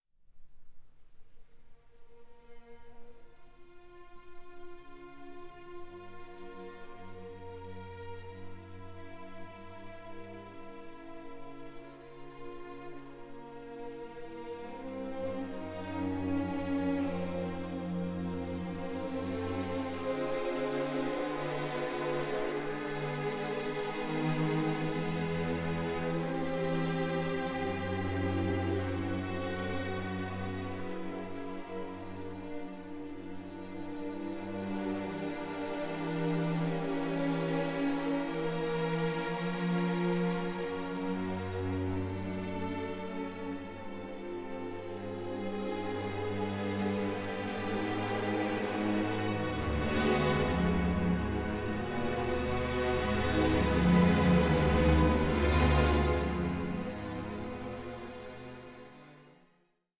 (48/24, 88/24, 96/24) Stereo  33,99 Select
heady, intoxicating atmosphere and vivid orchestration